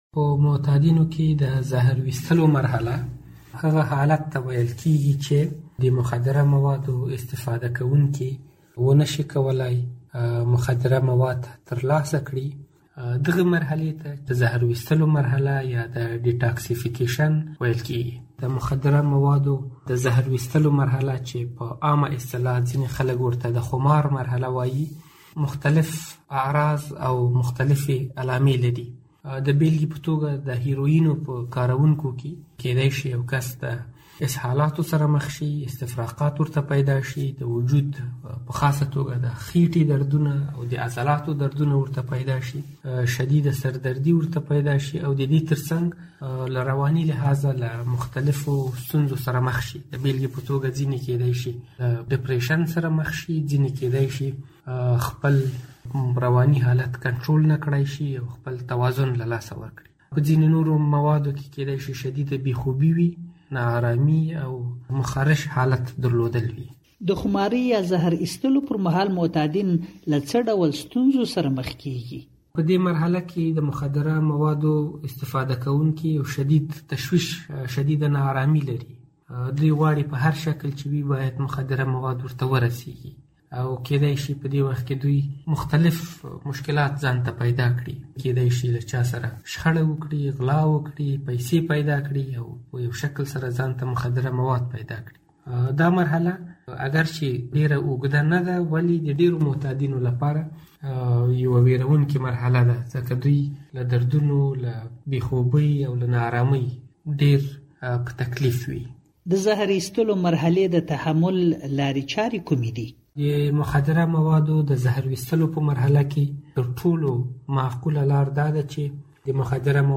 مرکه - صدا